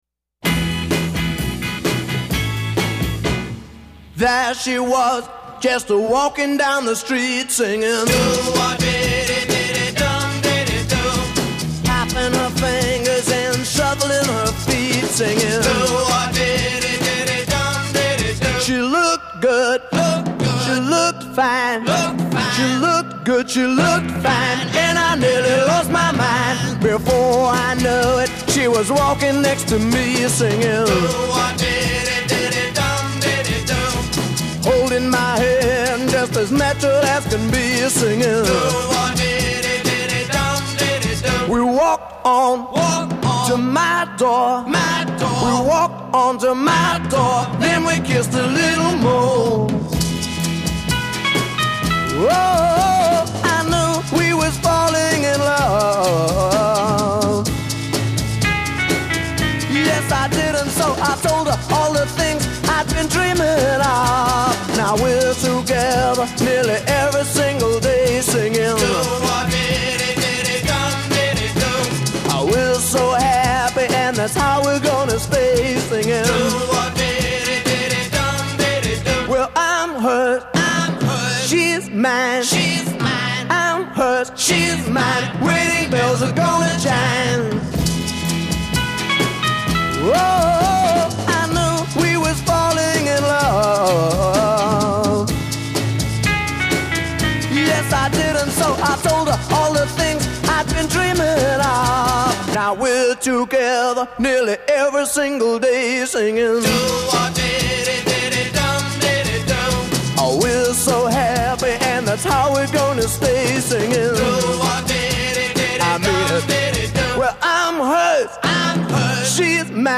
Additional studio musicians were also probably involved.
Intro   0:00 4 Organ statement of the hook melody.
p2 : 8 Responsorial organization to unison
(timpani with piano accompany at end)
Chorus   : 16 Voice and piano alternate on lead melody
bring guitar up in mix.   c
Coda     12 After fill material, repeat hook 3x and end.